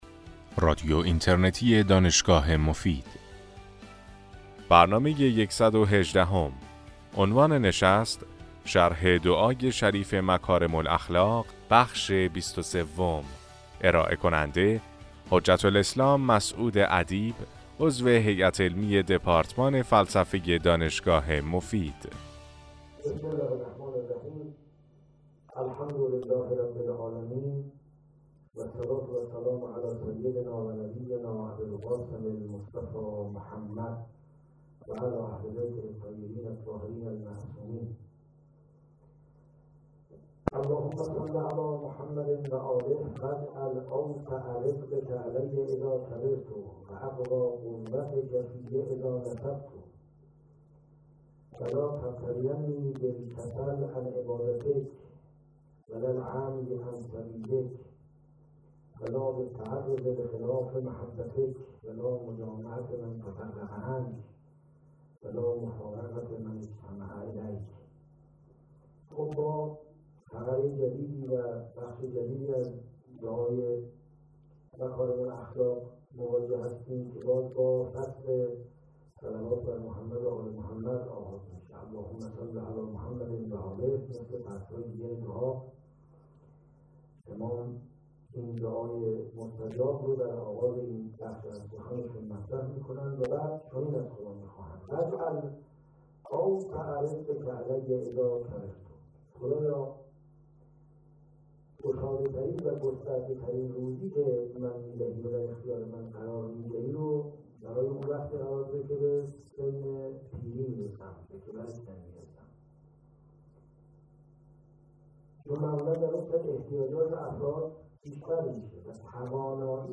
در این سلسله سخنرانی که در ماه رمضان سال 1395 ایراد شده است به شرح و تفسیر معانی بلند دعای مکارم الاخلاق (دعای بیستم صحیفه سجادیه) می پردازند.